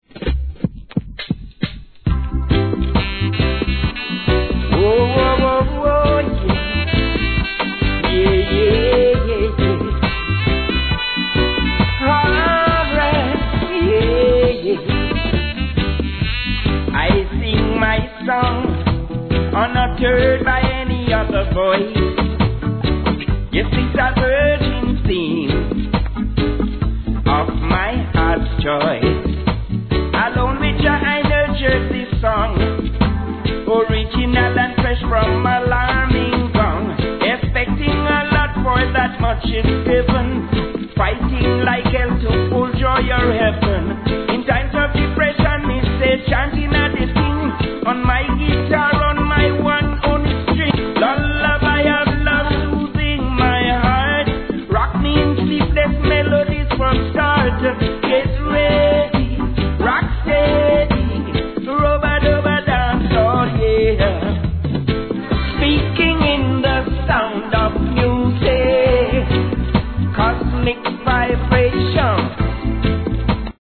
REGGAE
両面共にフォーンのメロディーが乗ったNICEミディアム♪